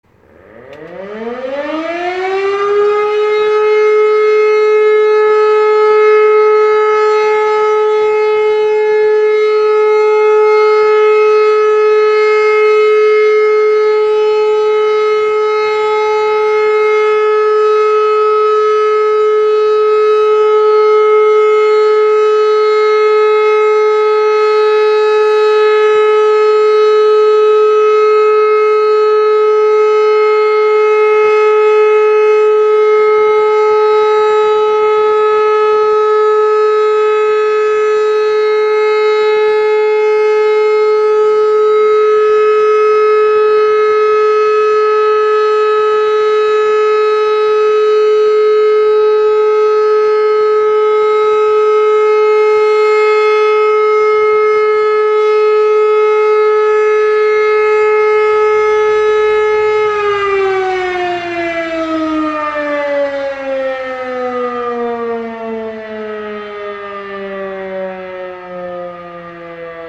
Sirene
Sirenensignale:
Entwarnung.m4a